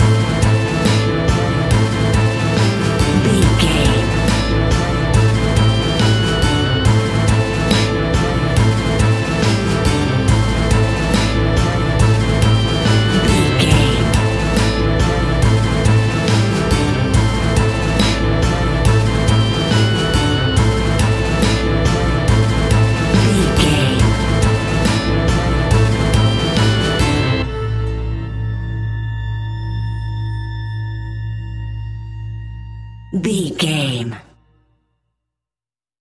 Aeolian/Minor
ominous
dark
suspense
eerie
electric organ
strings
acoustic guitar
harp
synthesiser
drums
percussion
spooky
horror music
horror instrumentals